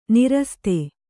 ♪ niraste